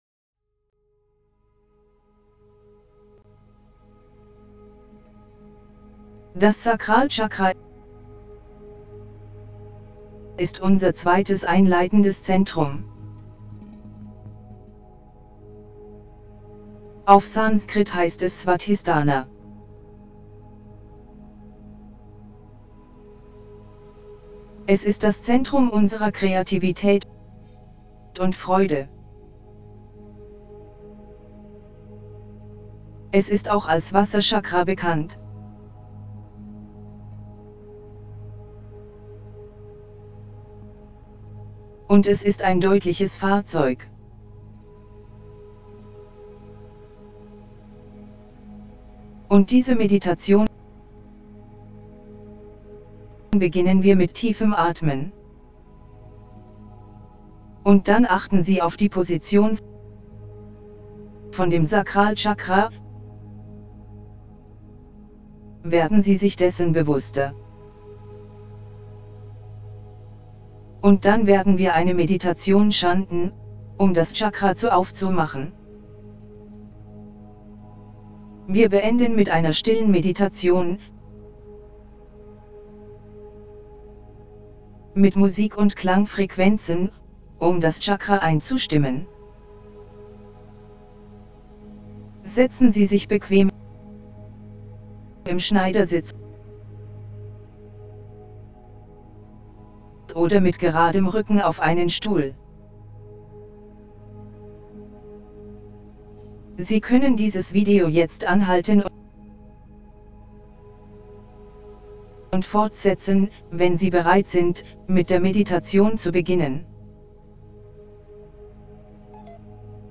2SacralChakraHealingGuidedMeditationDE.mp3